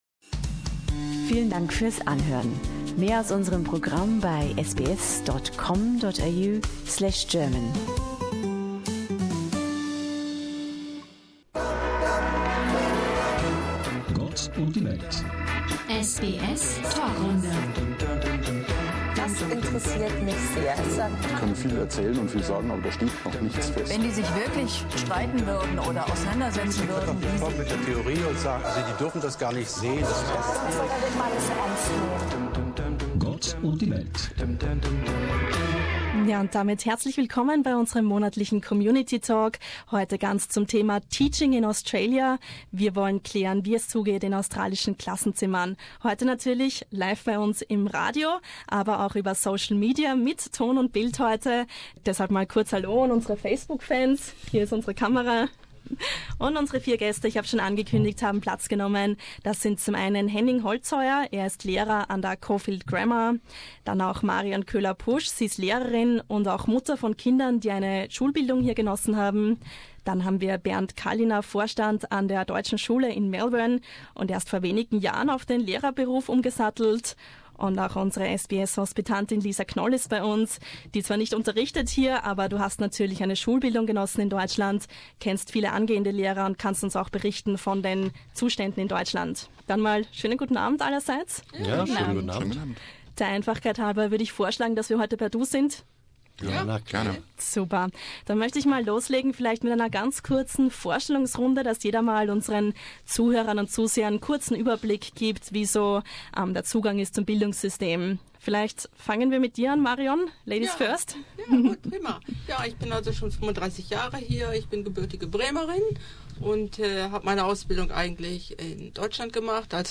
In unserer Panelrunde "Gott und die Welt" diskutieren vier Gäste, wie es um das australische Bildungssystem und im speziellen um den Lehrerberuf bestellt ist.